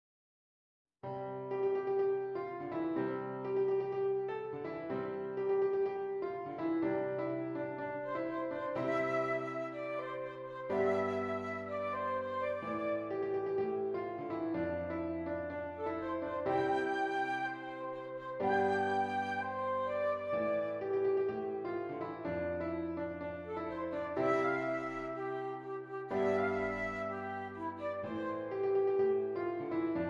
Flute Solo with Piano Accompaniment
C Major
Moderately